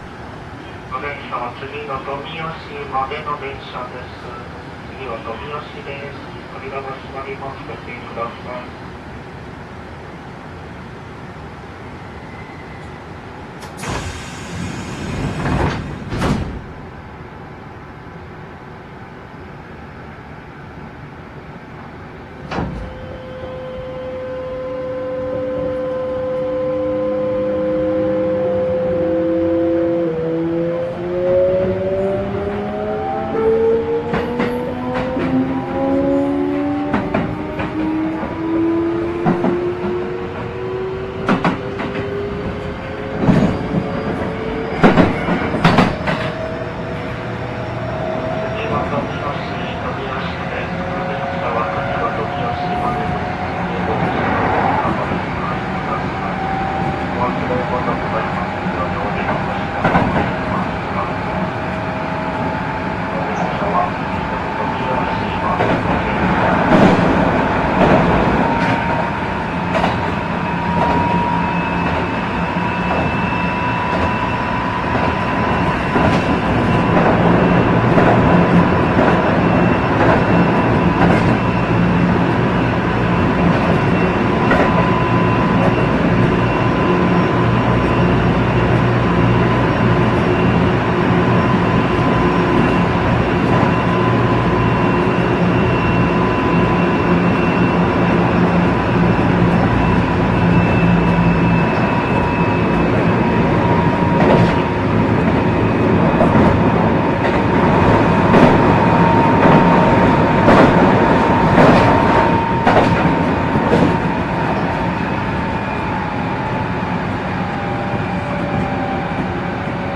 5200系でもそうなのですが、歯数比6.31の車両では非同期から同期に移る部分が滑らかになる傾向がありますね。
VVVFは近鉄の三菱GTOを採用している通勤車（1420系、3200系は除く）と同じ音がします。
走行音(5200系 5203・歯数比6.31)
収録区間：名古屋線 蟹江→富吉